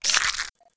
rifle_sniper_mode_on.wav